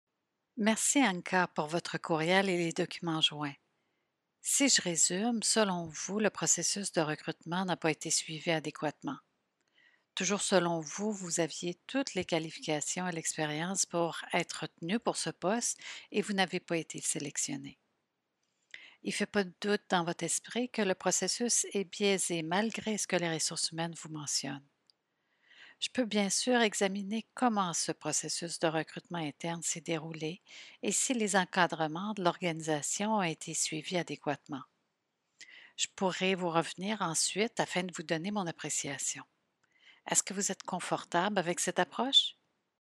Ces entretiens fictifs sont basés sur un amalgame d’expériences vécues.
une employée qui se plaint d’un processus de recrutement inadéquat visant un poste affiché à l’interne.